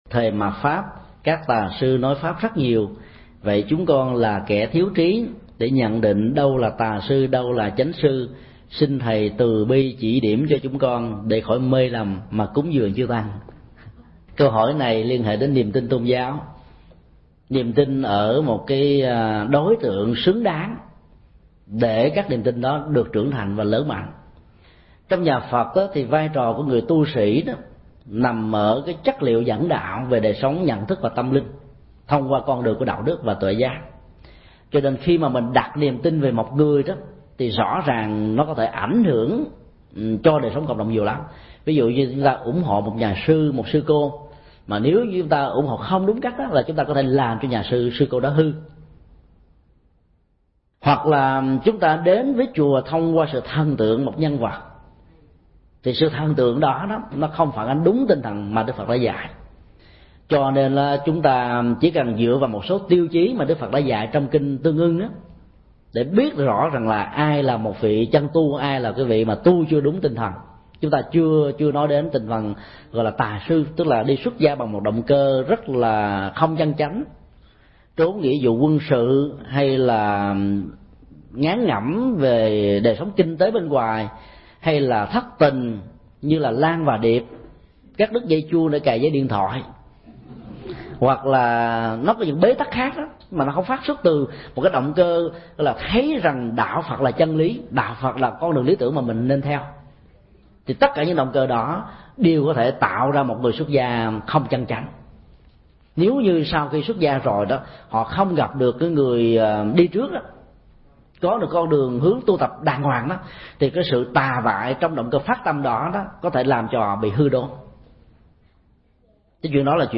Vấn đáp: Làm cách nào nhận biết người chân tu – thầy Thích Nhật Từ